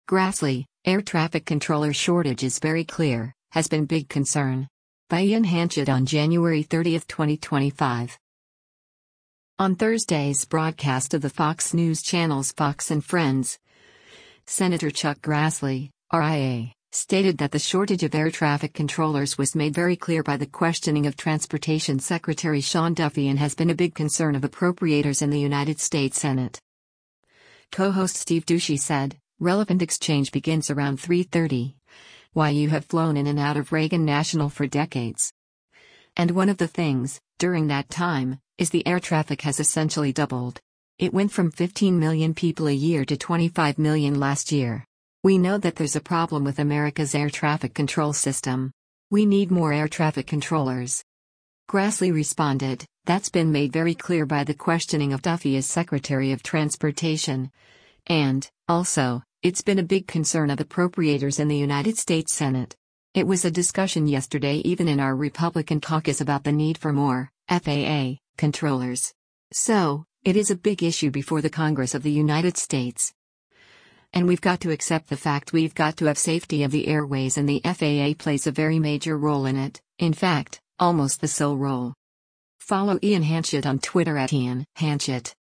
On Thursday’s broadcast of the Fox News Channel’s “Fox & Friends,” Sen. Chuck Grassley (R-IA) stated that the shortage of air traffic controllers was “made very clear” by the questioning of Transportation Secretary Sean Duffy and has “been a big concern of appropriators in the United States Senate.”